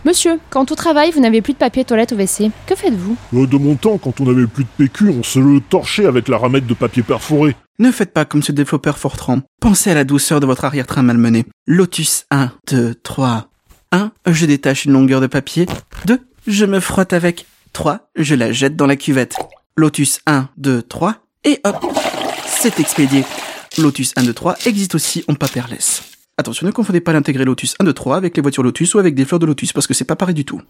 Chroniques › Fausse publicité
Extrait de l'émission CPU release Ex0241 : lost + found (avril 2026).
[bruit de chasse d'eau] c'est expédié ! Lotus 1 2 3, existe aussi en paper-less [bruit de litière qui semble remuée par un animal] [très rapide] Attention ne confondez pas l'intégré Lotus 1 2 3 avec les voitures Lotus ou avec les fleurs de lotus, parce que c'est pas pareil du tout !